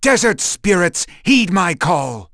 Zafir-Vox_Skill4.wav